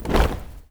AFROFEET 1-R.wav